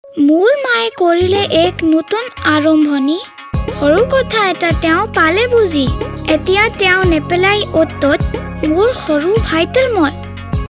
Radio spot Hindi TSC child excreta disposal pit children